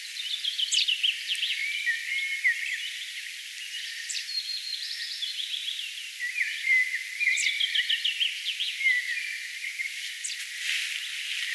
peipon poikanen kerjää ruokaa
peipon_lentopoikanen_kerjaa.mp3